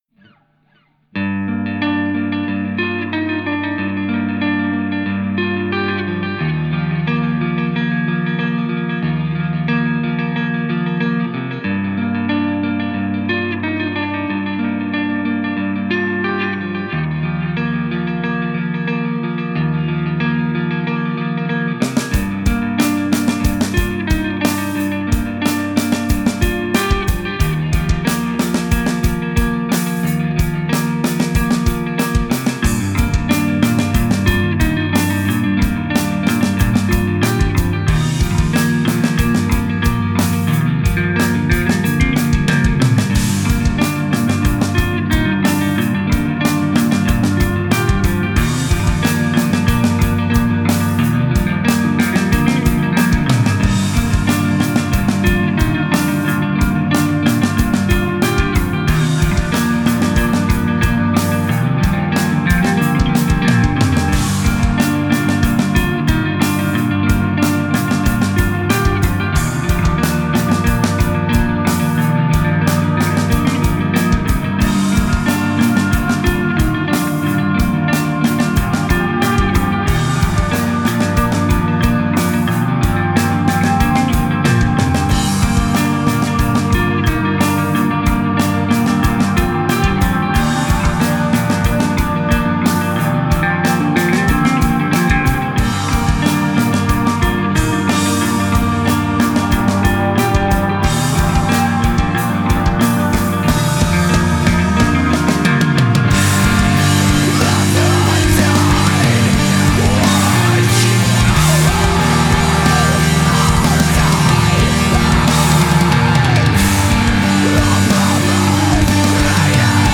Groupe bien ancré dans le post hardcore
chant saturé
une part mélodique et shoegaze